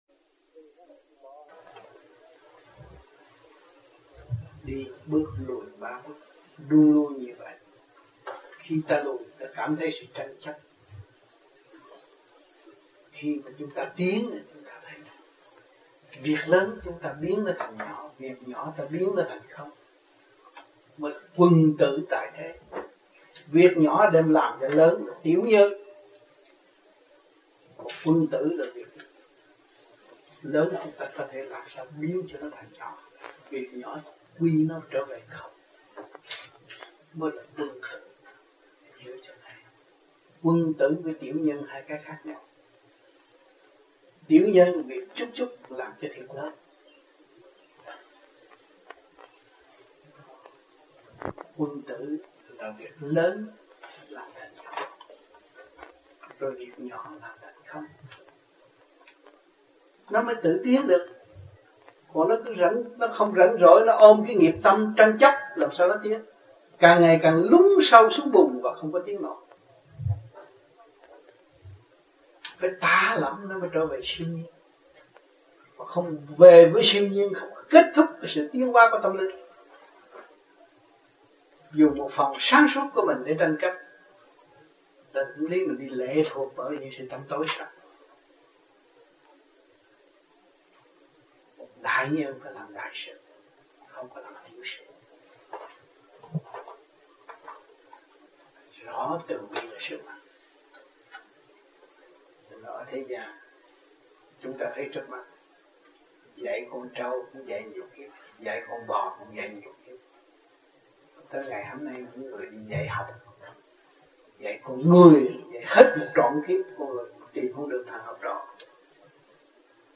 1986-06-08 - TV HAI KHÔNG - KHÓA BI TRÍ DŨNG 6 - BÀI GIẢNG VÀ VẤN ĐẠO